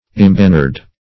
Imbannered \Im*ban"nered\, a. Having banners.